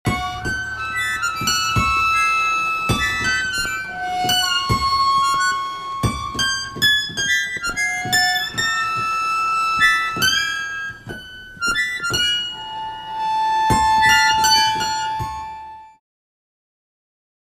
acc. git
accordion
cello